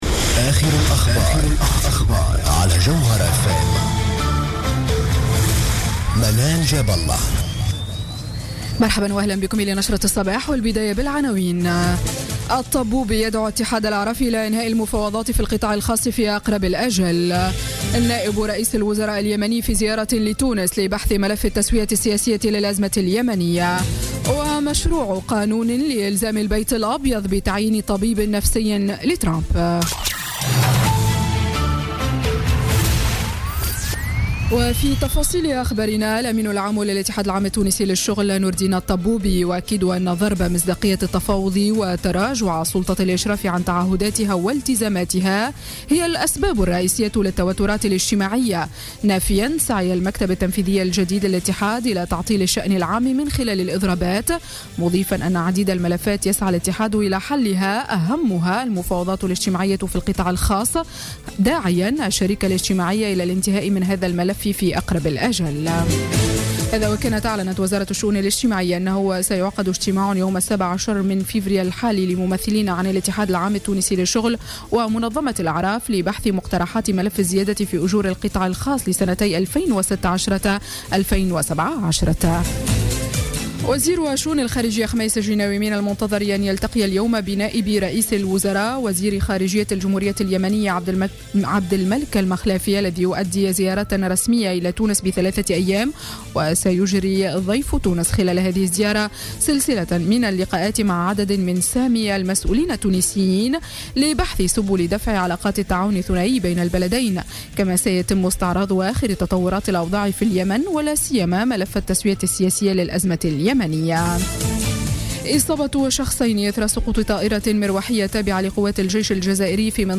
نشرة أخبار السابعة صباحا ليوم الاثنين 13 فيفري 2017